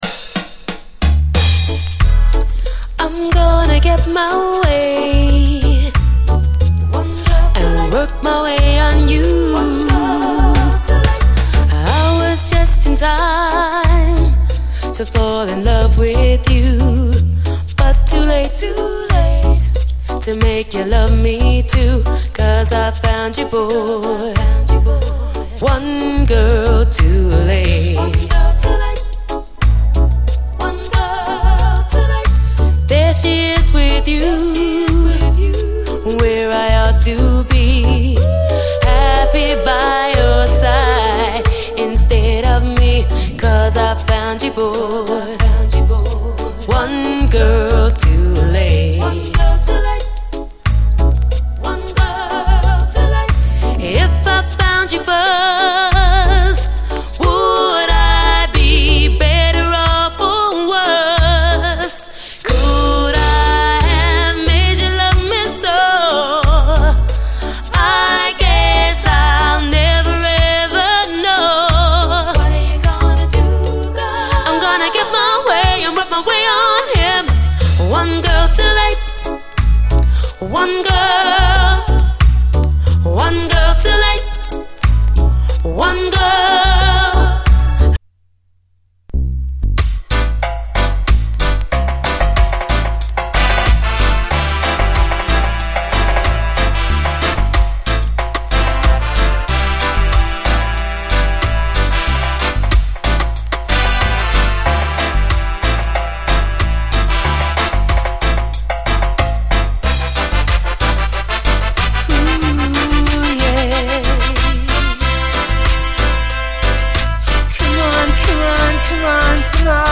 REGGAE
ライトな質感が心地よい80’Sラヴァーズが並びます！